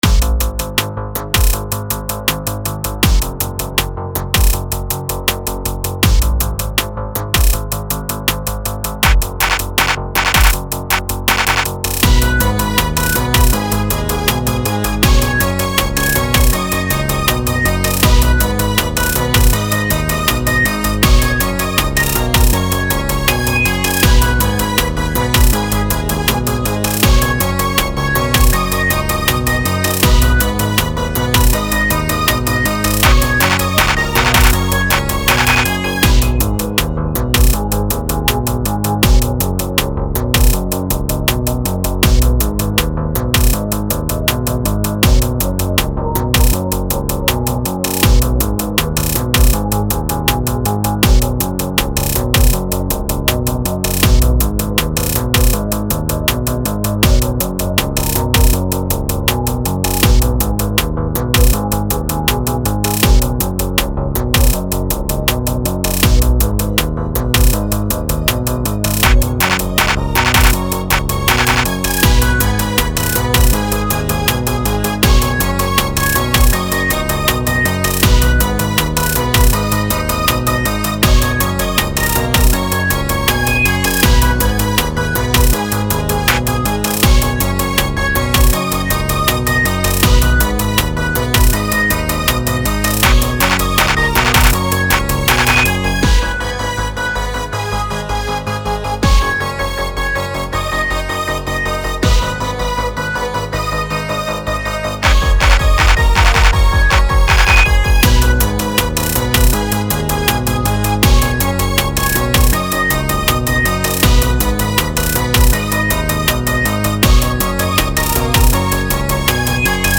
Rap Дерзкий 80 BPM